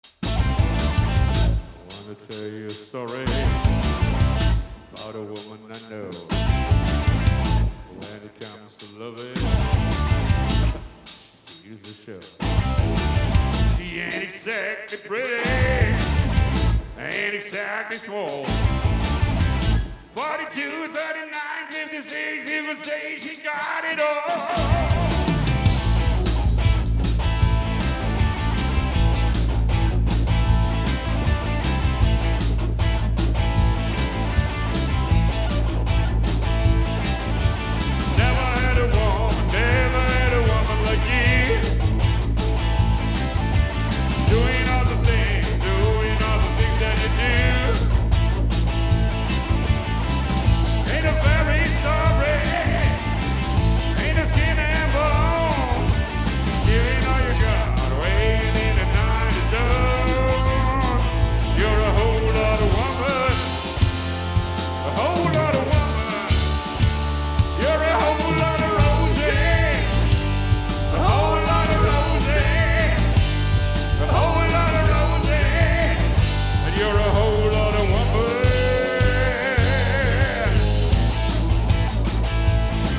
(Proberaumaufnahme)